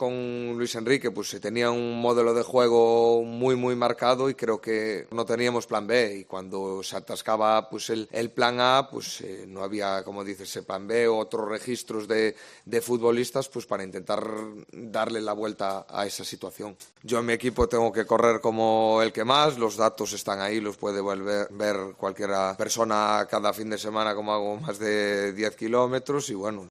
Rueda de prensa selección española de fútbol